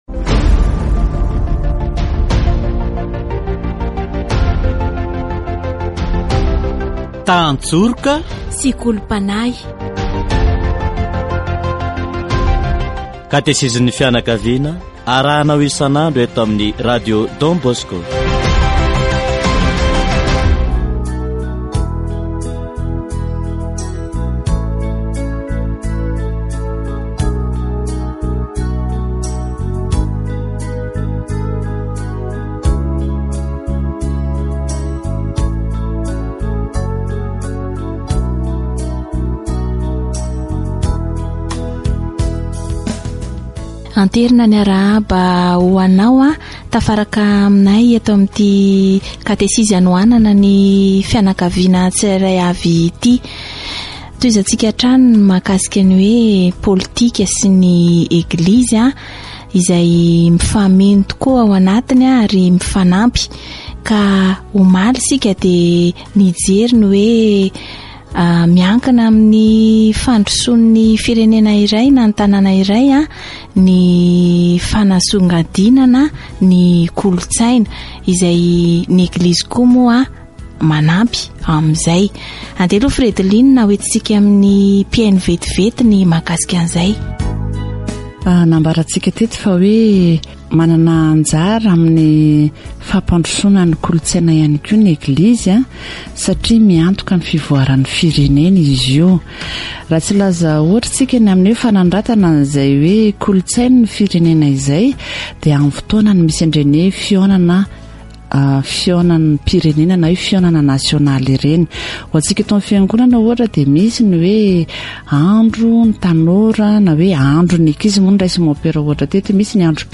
Ny fahalalahan'ny fanehoana hevitra dia isan'ireo singa hampandrosoana ny firenena. Katesizy momba ny politika araka ny fijerin'ny Eglizy